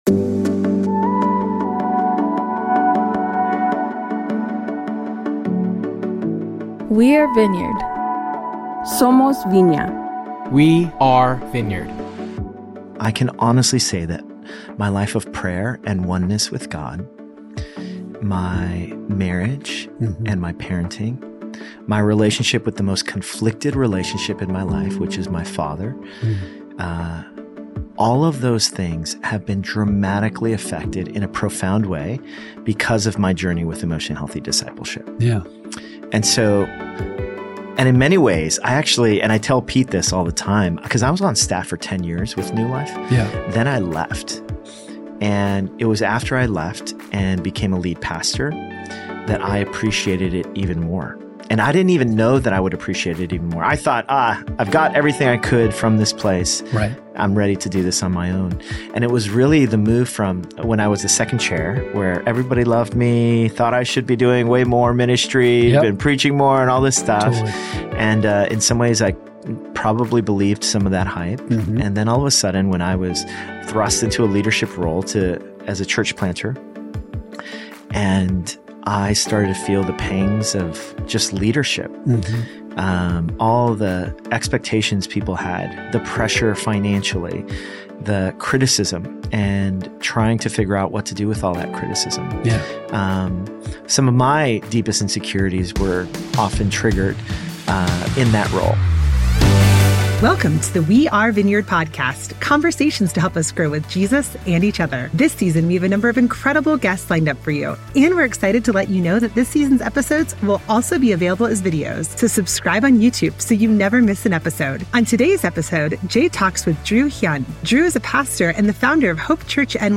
This is part one of a two-part conversation, so join us next week to hear more!